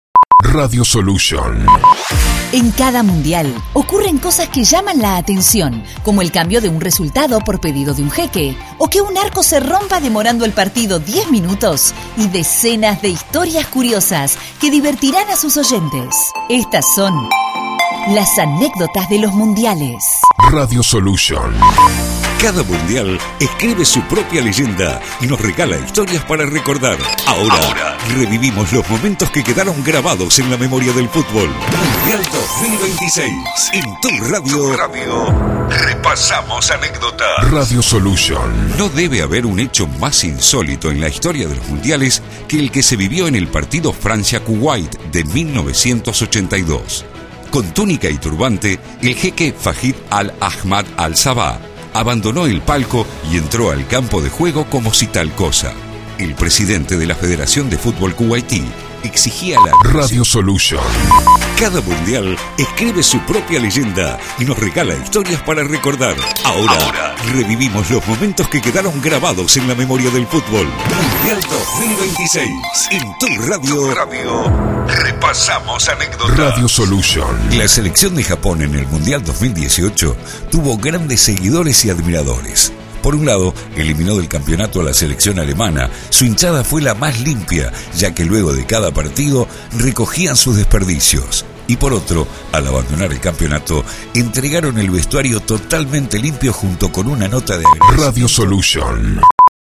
Editados con Música y Efectos
Producción confeccionada a 1 voces